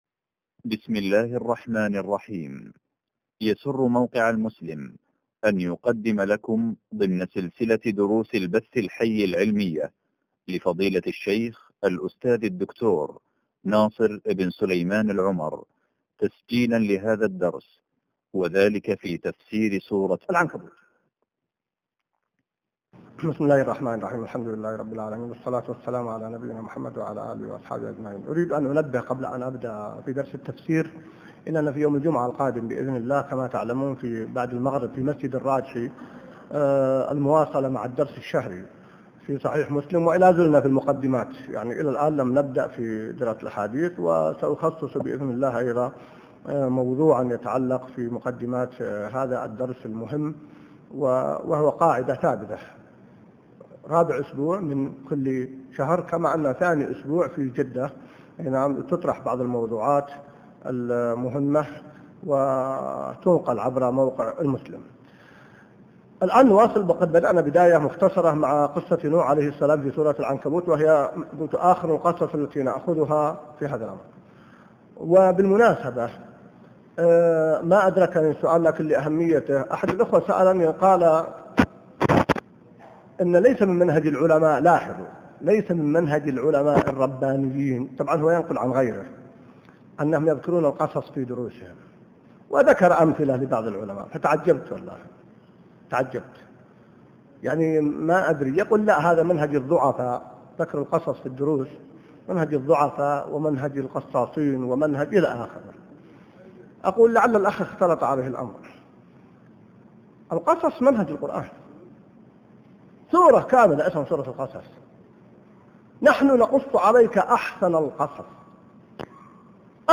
الدرس 54 من تفسير سورة العنكبوت | موقع المسلم